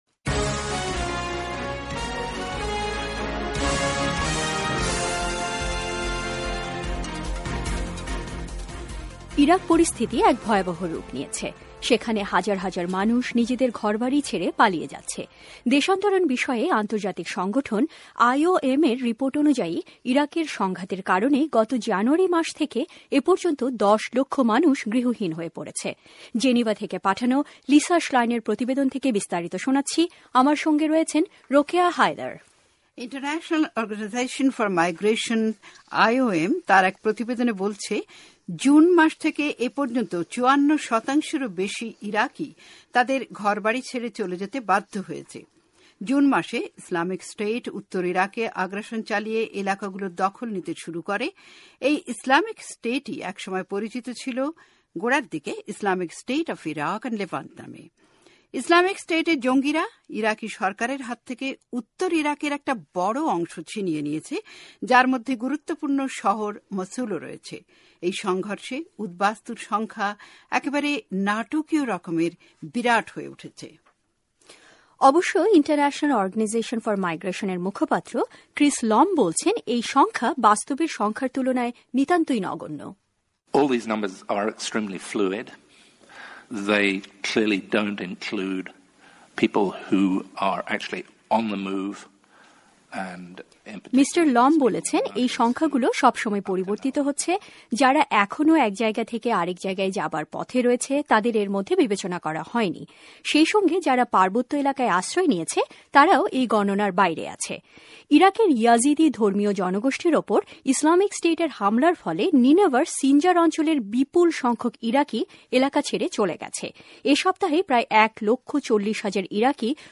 ইরাকের উদ্বাস্তুদের নিয়ে একটি বিশেষ প্রতিবেদন